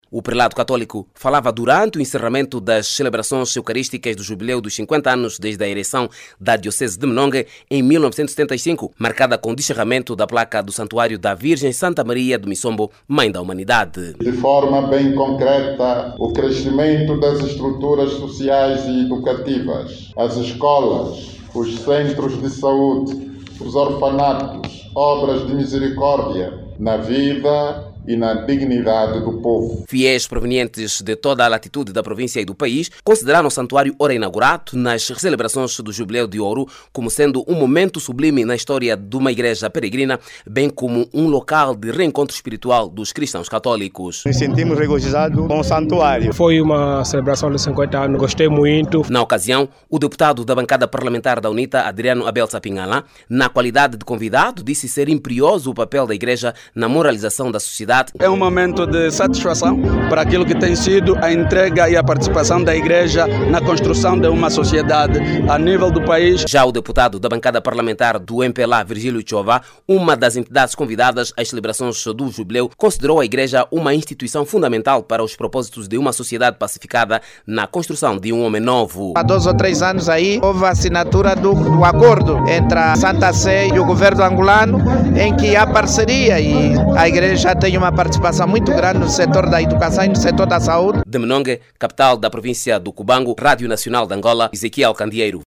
O Bispo de Saurimo e Presidente da CEAST, apela a maior sensibilidade no apoio aos mais desfavorecidos para a sua ascenção social. Dom José Manuel Imbamba falava no Cubango no encerramento das celebrações do jubileu dos 50 anos da diocese de Menongue.